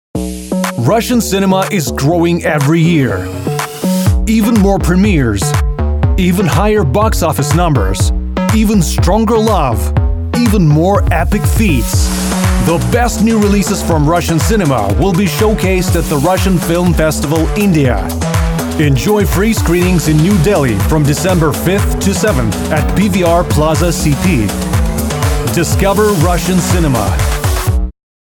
Une prestation rapide et de qualité studio pour des résultats exceptionnels.
Démo commerciale
Accent transatlantique / médio-atlantique.
Rode NT-1; Audience ID14
Baryton